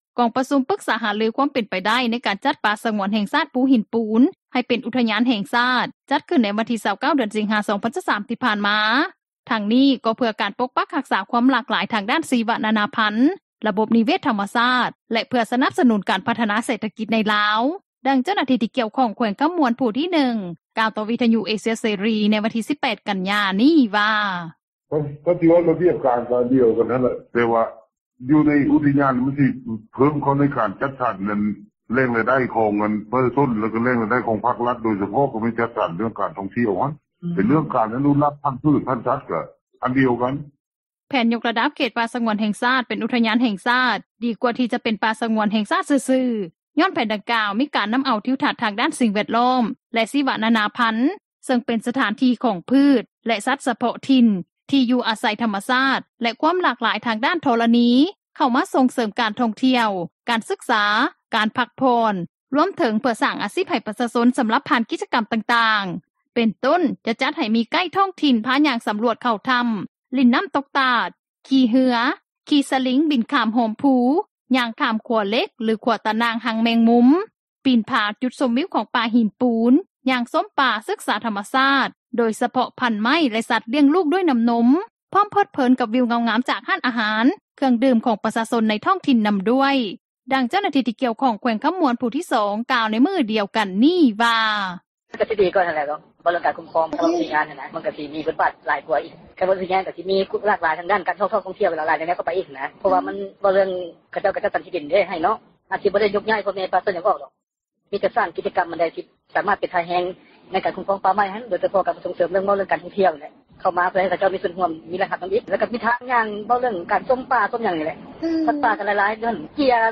ດັ່ງເຈົ້າໜ້າທີ່ ທີ່ກ່ຽວຂ້ອງ ແຂວງຄໍາມ່ວນ ຜູ້ທີ 2 ກ່າວໃນມື້ດຽວກັນນີ້ວ່າ:
ດັ່ງປະຊາຊົນ ເມືອງທ່າແຂກ ກ່າວໃນມື້ດຽວກັນນີ້ວ່າ:
ດັ່ງຍານາງ ກ່າວວ່າ: